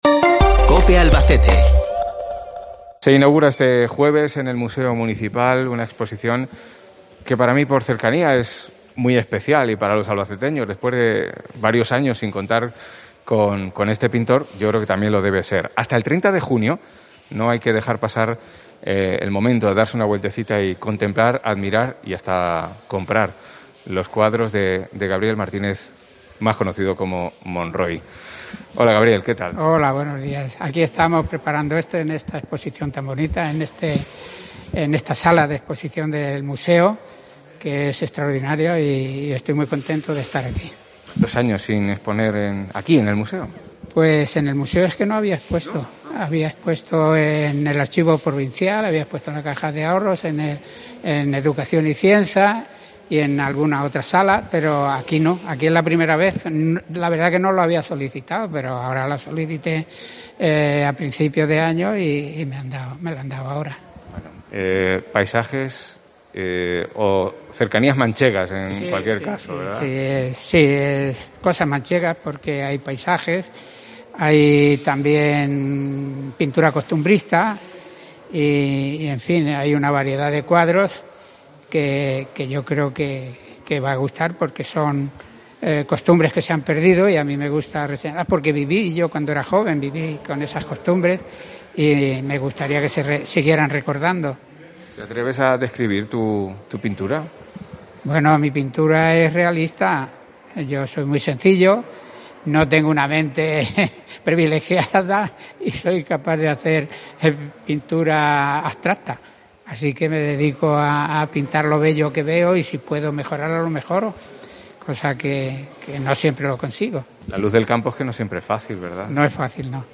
Albacete provincia ENTREVISTA COPE La Mancha y sus oficios